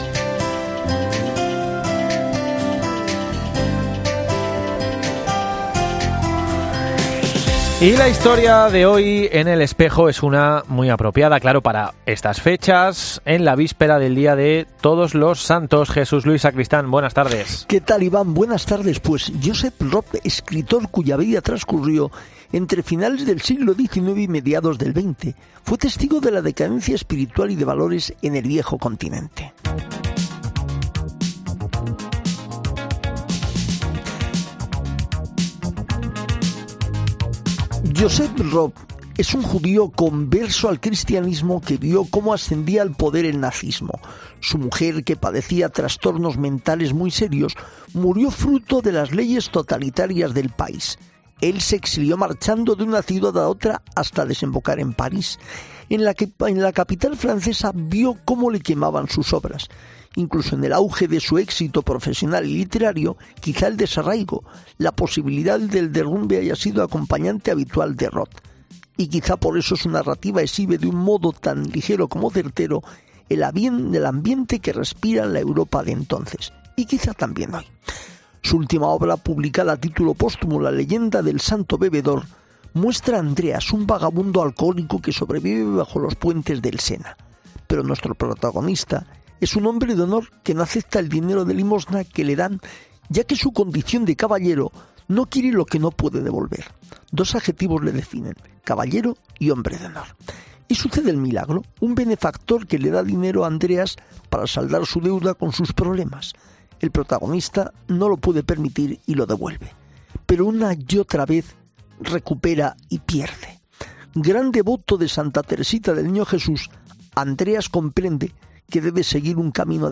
Ángelus.